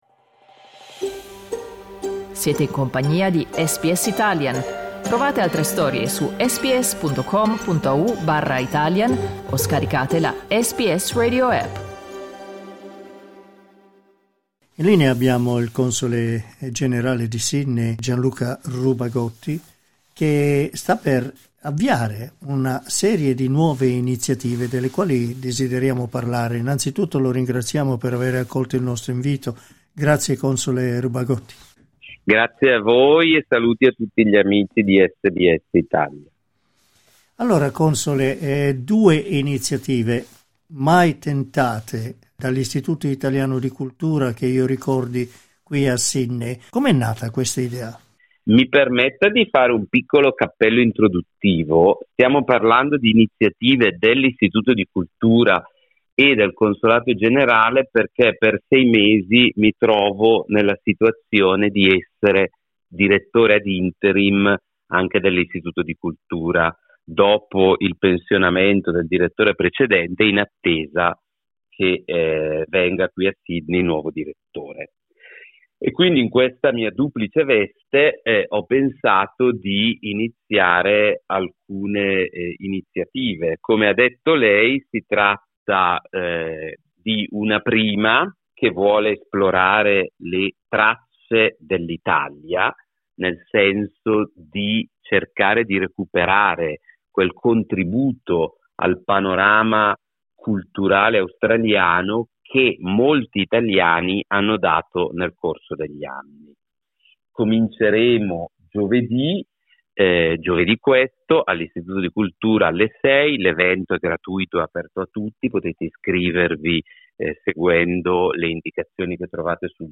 Clicca sul tasto "play" in alto per ascoltare l'intervista Il primo incontro della serie Traces of Italy sarà dedicato al libro Carlo Felice Cillario, Italian Maestro of the Australian Opera del prof. Stephen Mould.